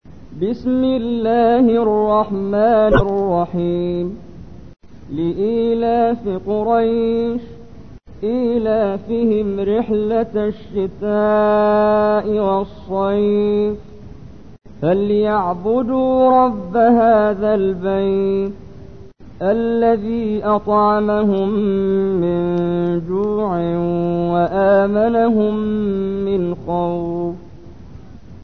تحميل : 106. سورة قريش / القارئ محمد جبريل / القرآن الكريم / موقع يا حسين